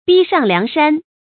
bī shàng liáng shān
逼上梁山发音
成语正音 逼，不能读作“bí”。